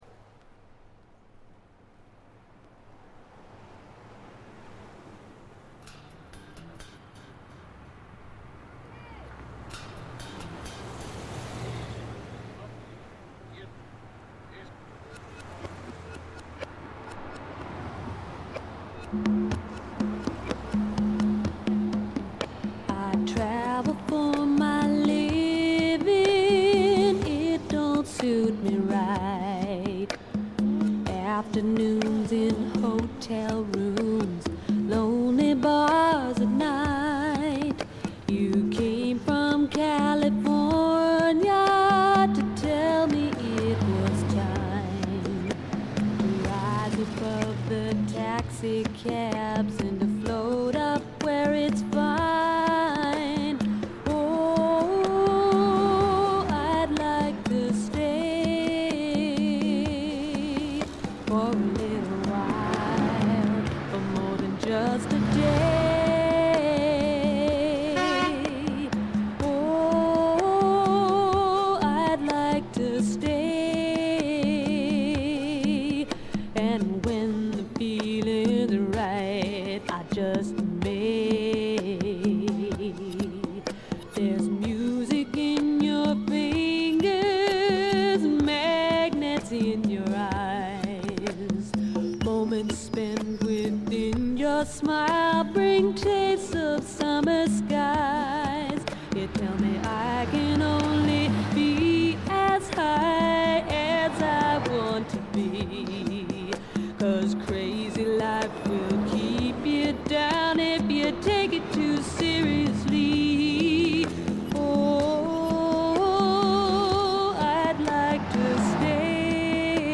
わずかなノイズ感のみ。
気を取り直して・・・これはシアトル産の自主制作盤で、知られざるAOR系女性シンガーソングライターの快作です。
試聴曲は現品からの取り込み音源です。
Recorded At - Sea-West Studios, Seattle